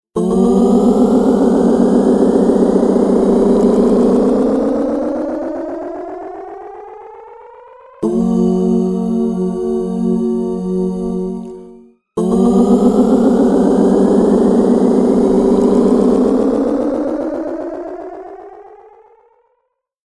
MicroPitchは、サウンドを自然に太くする高解像度ピッチシフトと、ドラマチックなスラップバックを生み出すディレイを融合したプラグインです。
MicroPitch | Vocals | Preset: Vocal Climb
MicroPitch-Eventide-Vocals-Climb.mp3